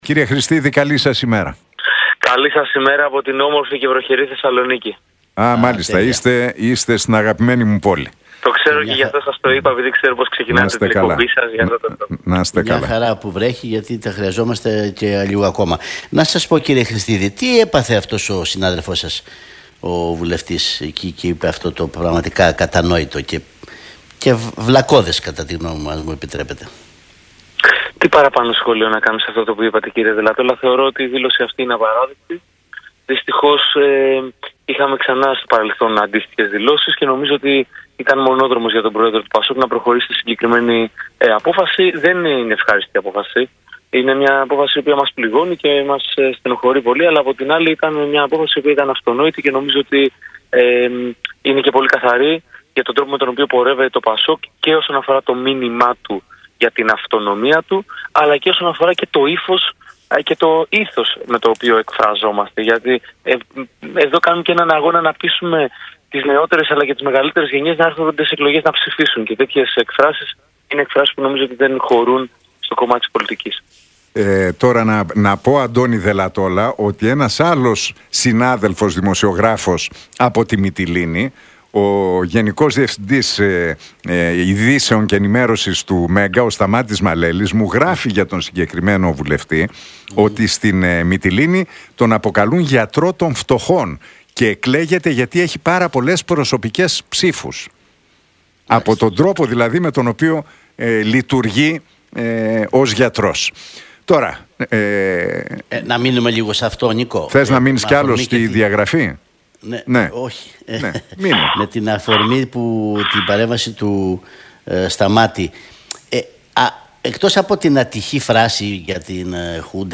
Χρηστίδης στον Realfm 97,8: Όσο μιλάμε για τη βελόνα, η βελόνα θα είναι εκεί που βρίσκεται - Το συνέδριο μπορεί και πρέπει να είναι ένα restart